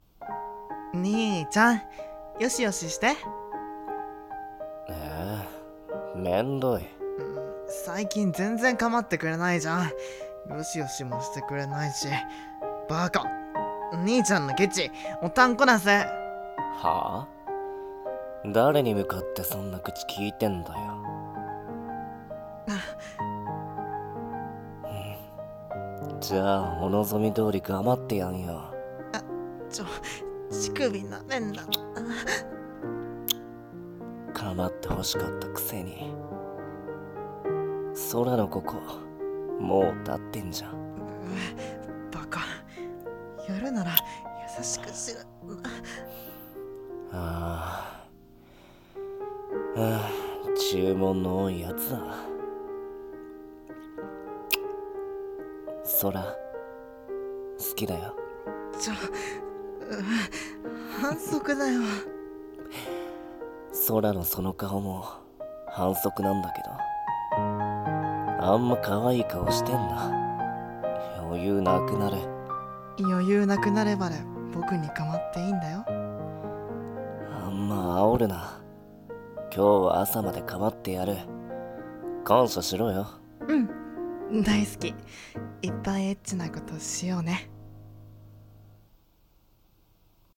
【BL声劇】かまちょな弟